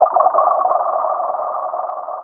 Index of /90_sSampleCDs/Best Service ProSamples vol.49 - FX-Area [AKAI] 1CD/Partition B/LIQUID-BUBBL